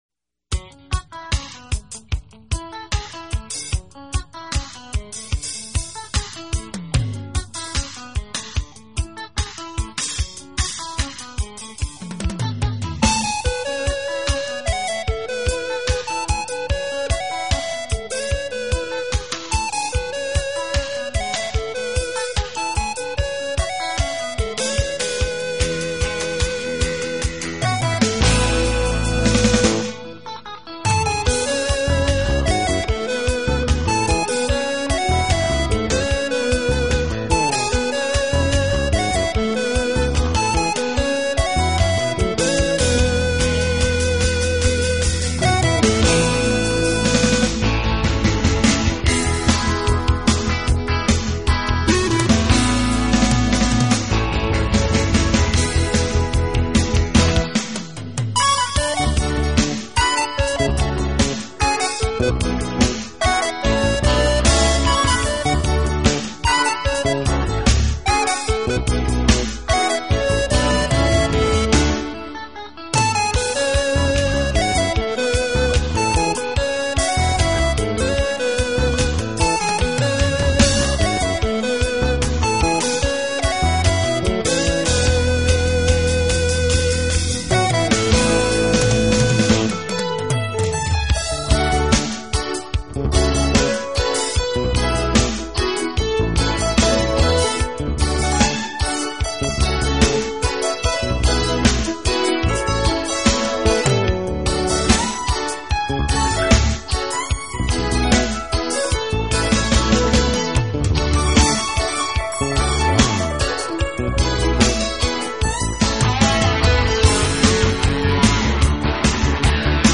Quality: VBR, erage 320kbps, stereo